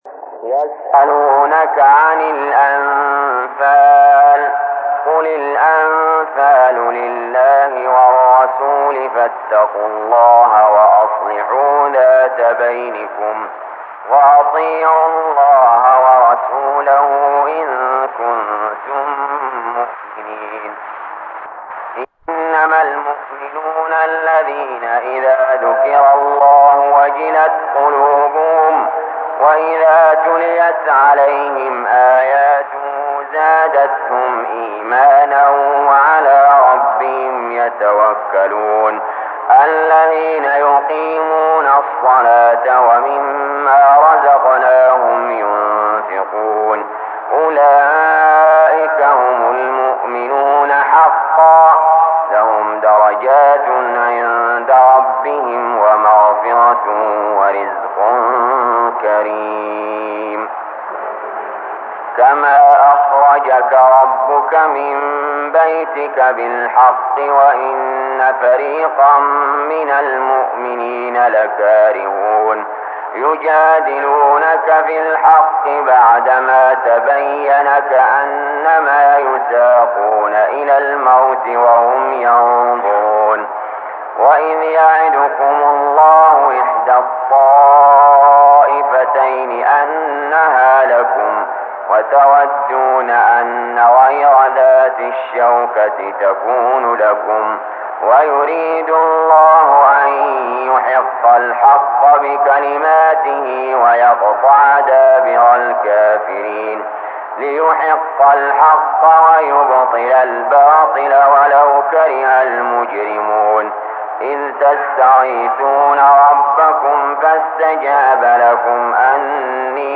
المكان: المسجد الحرام الشيخ: علي جابر رحمه الله علي جابر رحمه الله الأنفال